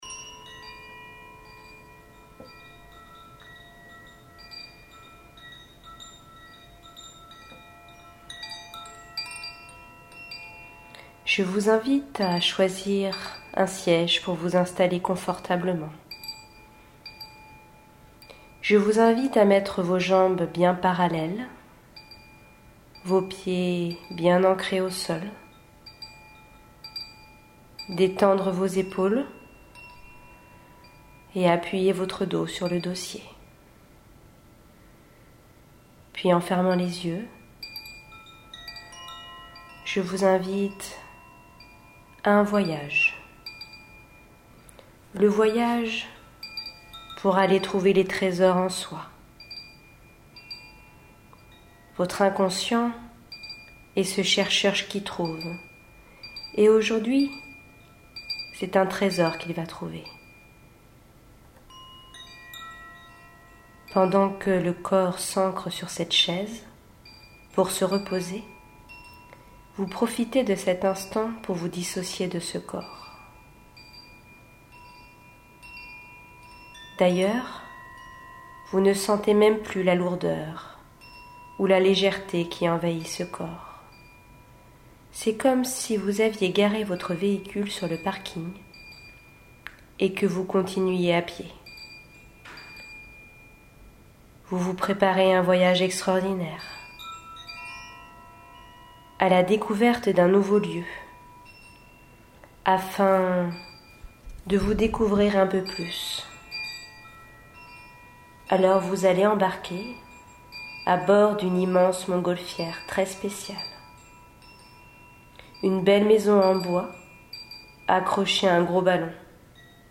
Méditation des forces de caractère